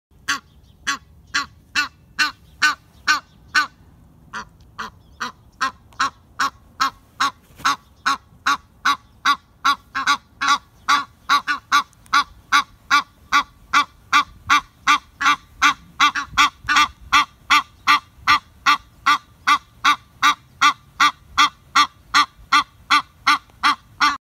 Oca egiziana
(Alopochen aegyptiaca)
Oca-egiziana.mp3